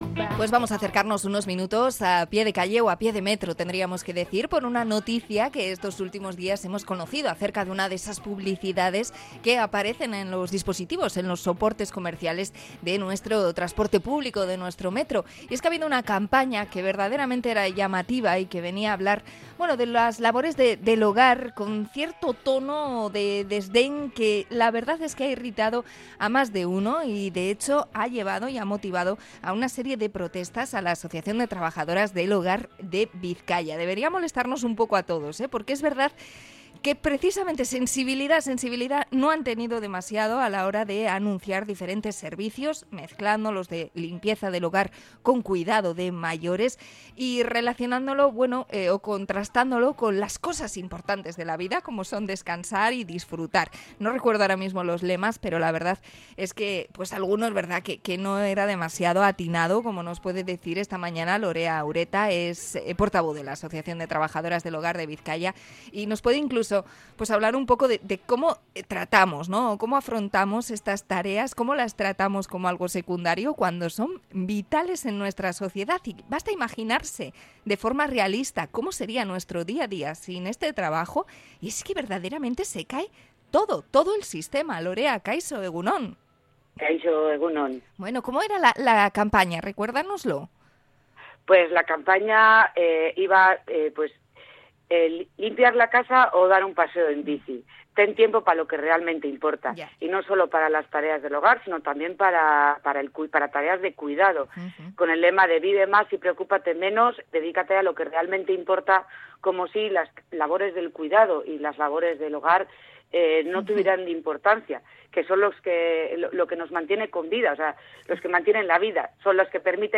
Entrevista a las trabajadoras del hogar por una campaña polémica
int.-trabajadoras-del-hogar.mp3